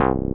noise9.mp3